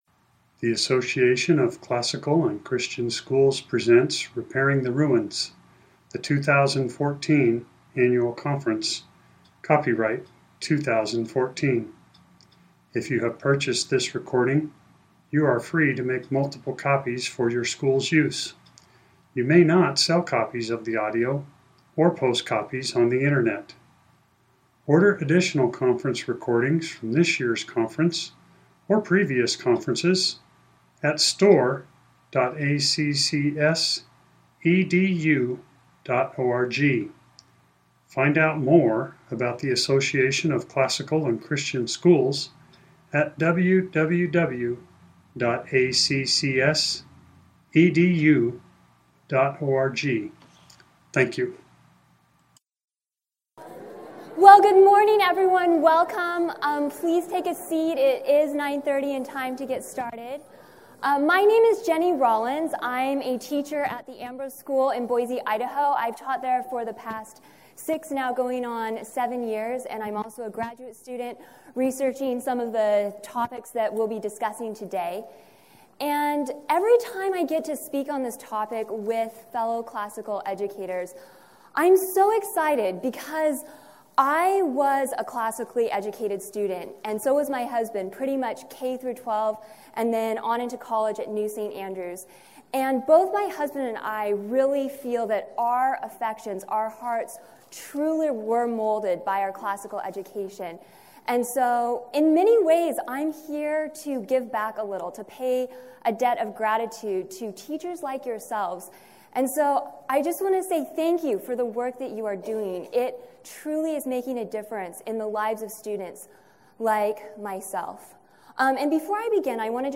2014 Workshop Talk | 0:58:44 | All Grade Levels, Virtue, Character, Discipline
The Association of Classical & Christian Schools presents Repairing the Ruins, the ACCS annual conference, copyright ACCS.